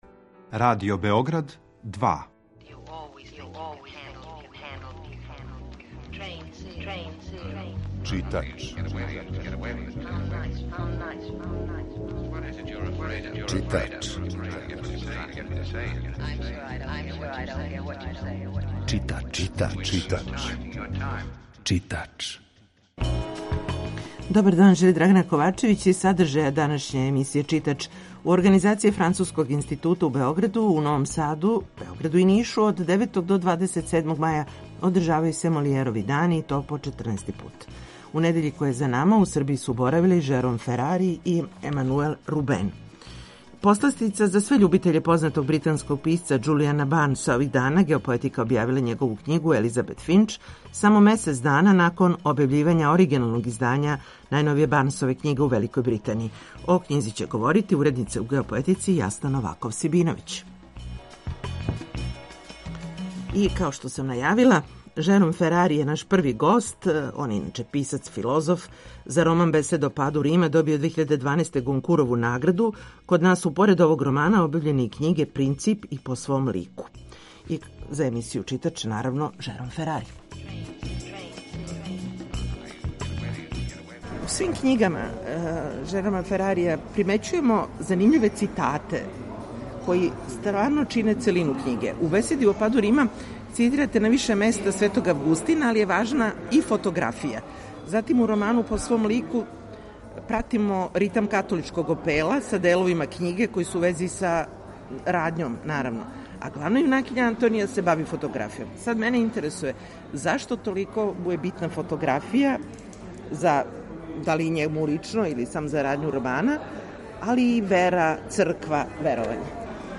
Емисија је колажног типа, али је њена основна концепција – прича о светској књижевности
За емисију Читач говори Жером Ферари.